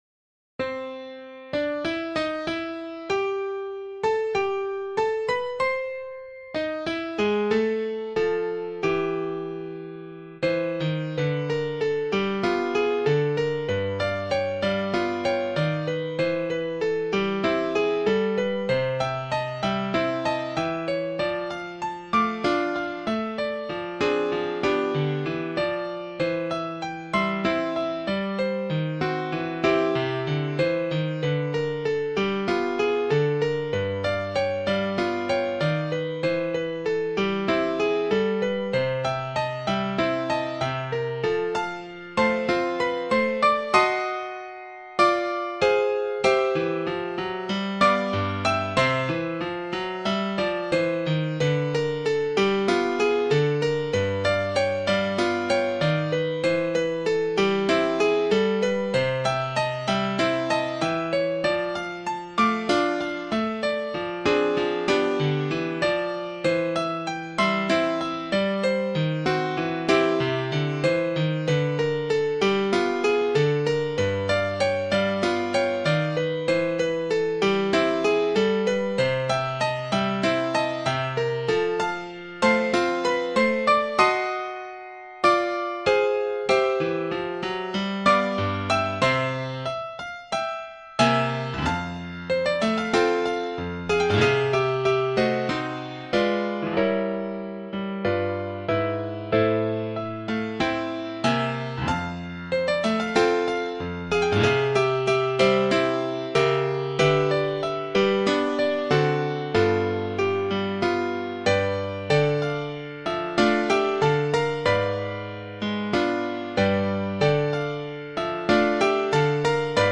for piano as an mp3 file or play it below